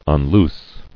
[un·loose]